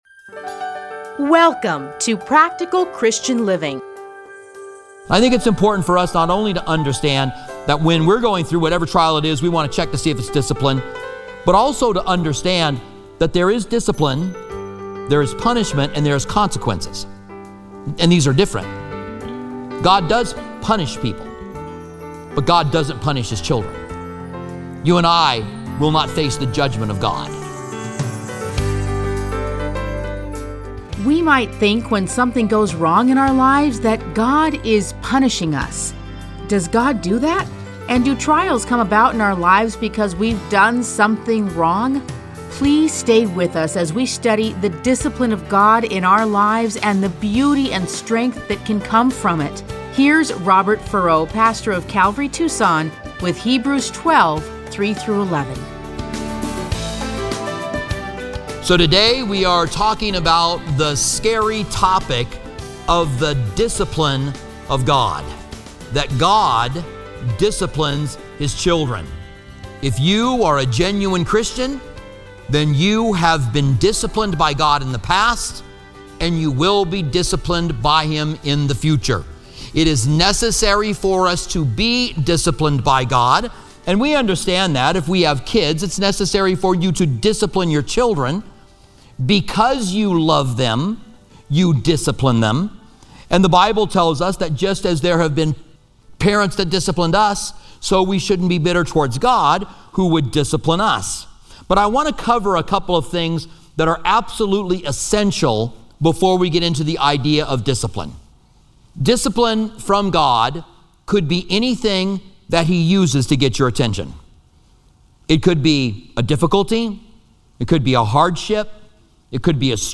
Listen to a teaching from Hebrews 12:3-11.